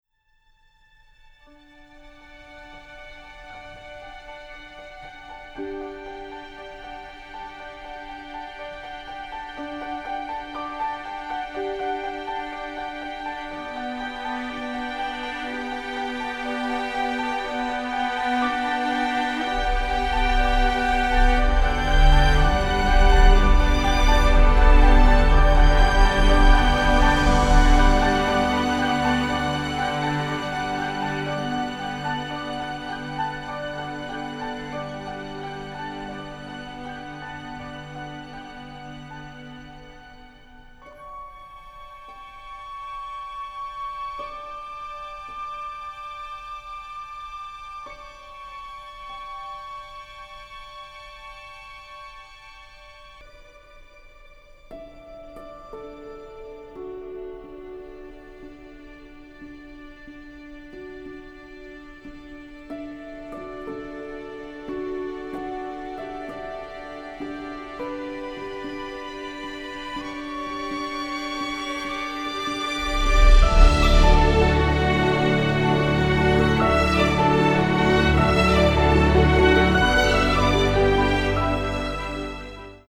encompassing tenderness, sadness and nostalgia